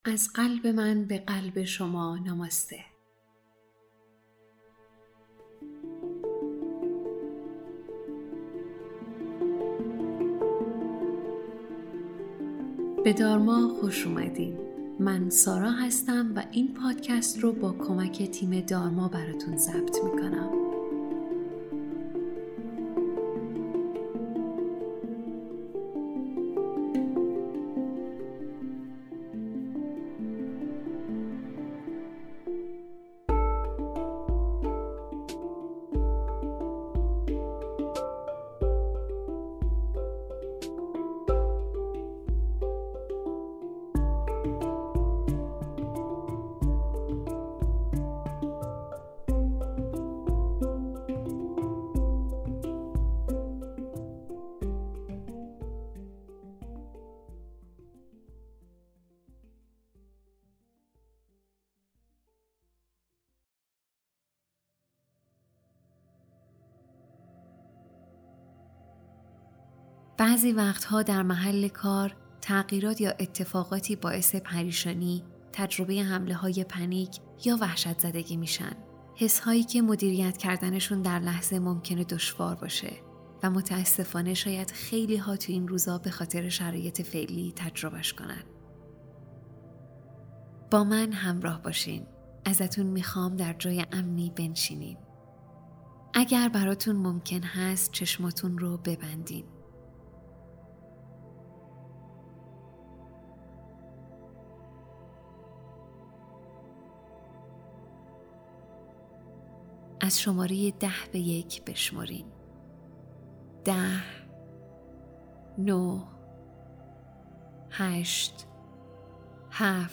این مدیتیشن برای حالت نشسته یا خوابیده مناسب هست